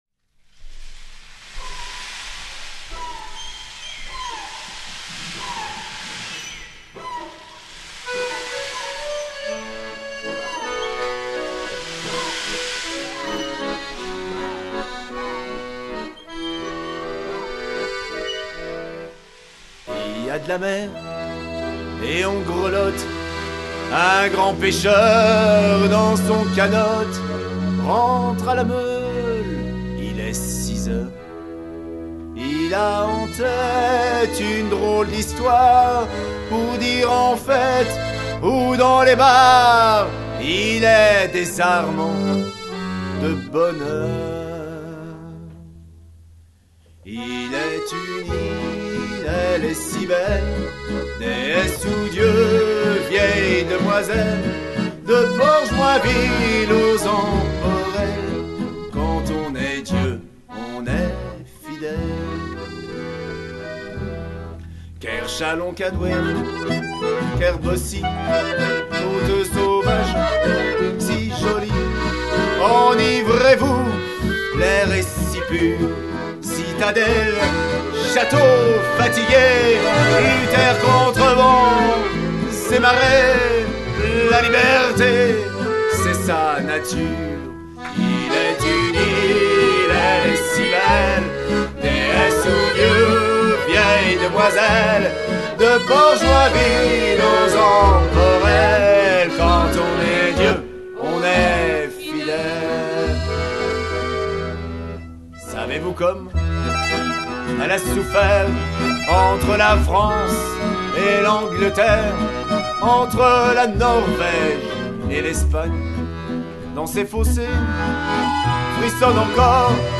contrebasse
cajon
guitare
chœur